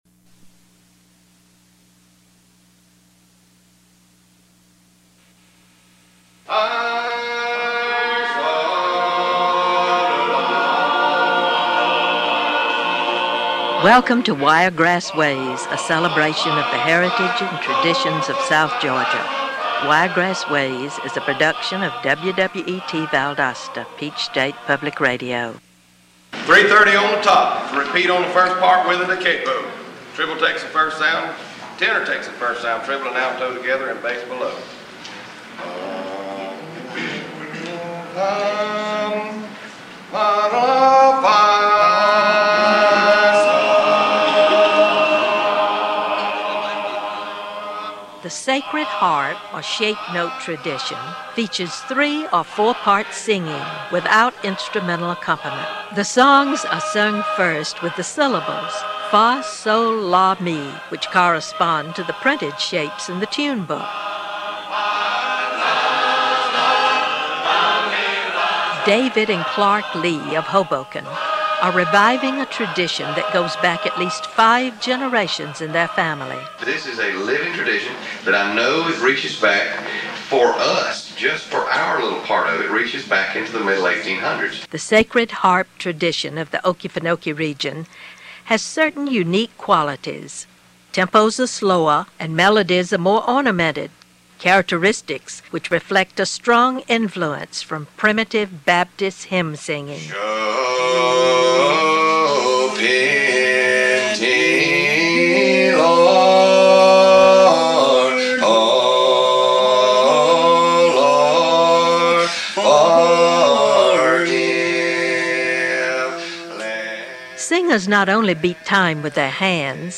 Georgia from Wiregrass Ways, originally broadcast on Georgia Public Radio, 1999
sacred-harp-school.mp3